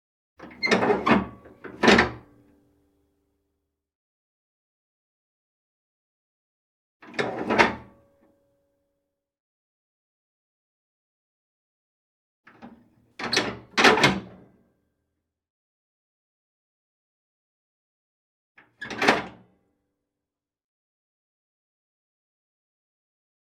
Iron Wood Burning Stove Open Damper Door Sound
household